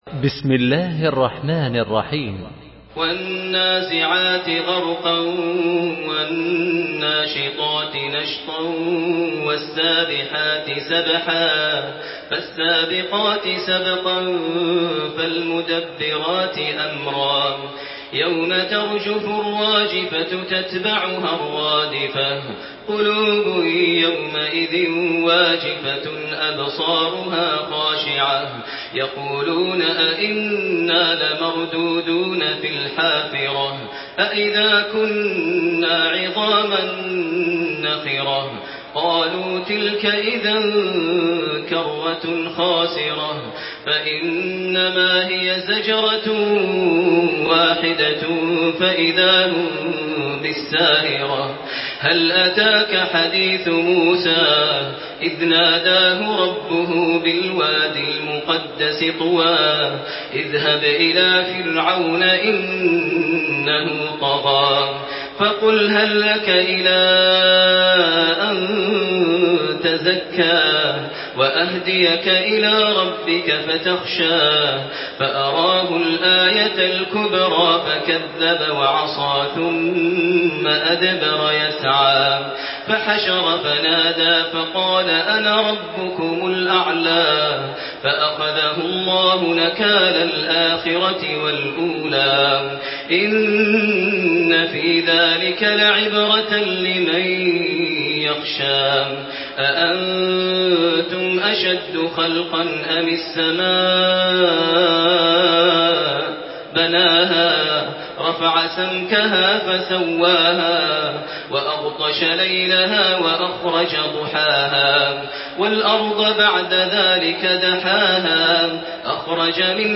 تحميل سورة النازعات بصوت تراويح الحرم المكي 1428
مرتل